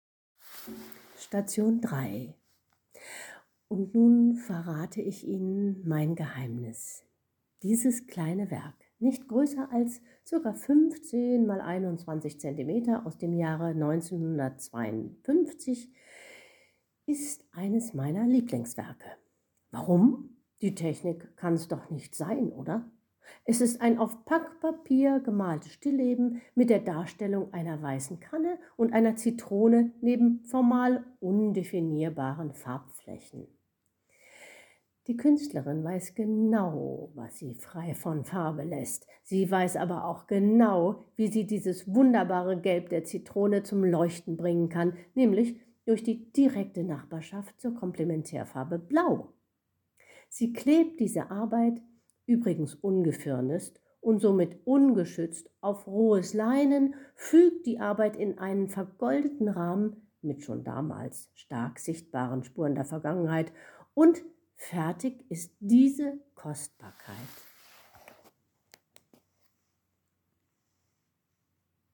Diese Führung entstand anlässlich der Kult(o)urnacht 2022.